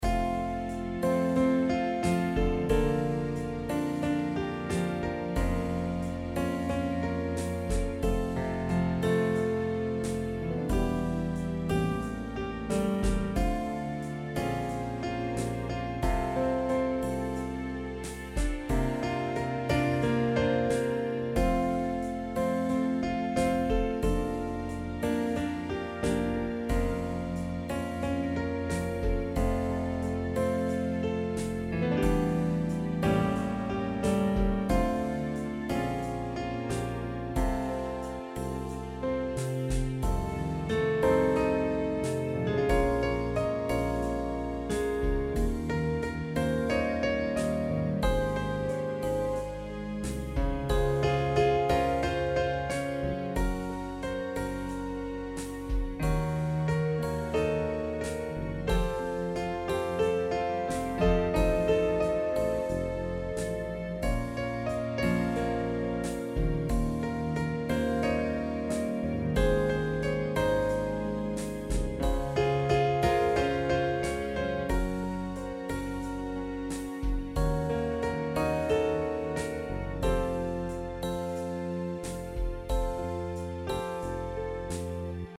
Relaxed, cocktail music Mood
Piano, strings Genre: Easy listening Composer/Artist
Loop Full Score